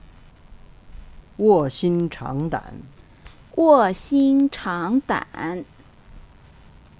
発音 mp3
发　音:wò xīn cháng dǎn